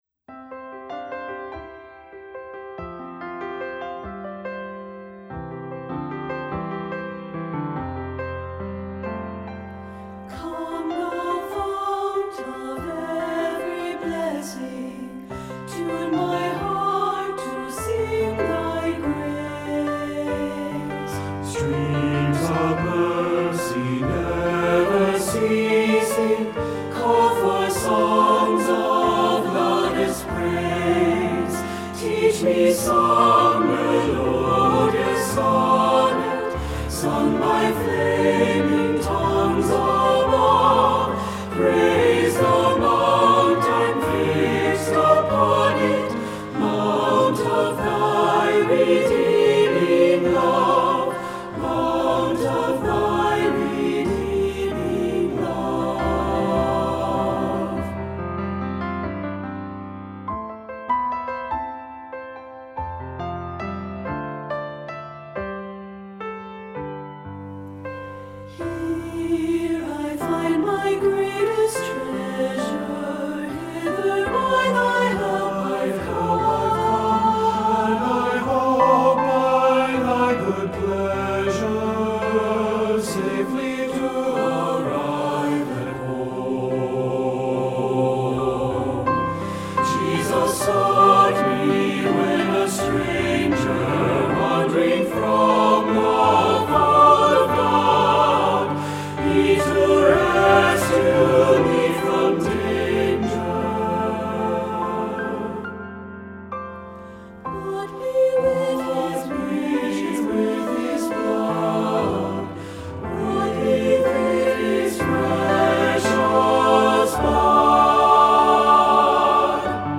SATB with piano